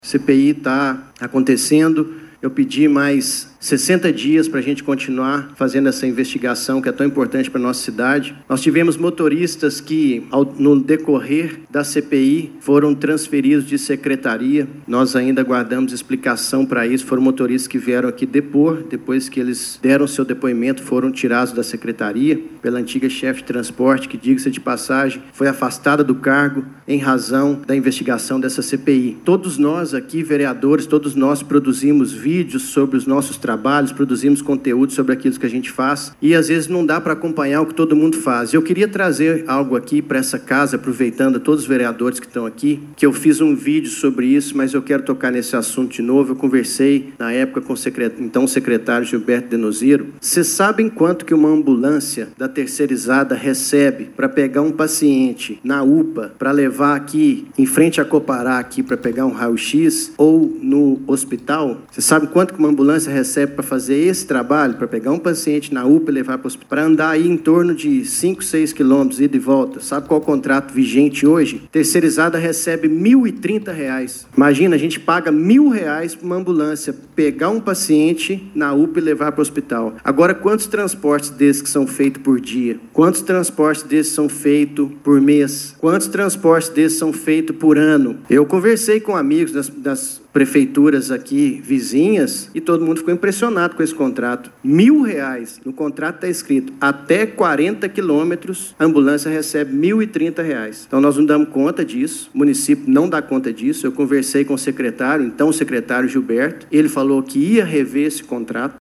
O vereador Leonardo Xavier Assunção Silva (Novo), autor da proposta de abertura da investigação, utilizou seu tempo na tribuna para atualizar os trabalhos da Comissão Parlamentar de Inquérito (CPI), instaurada em 18 de novembro de 2025.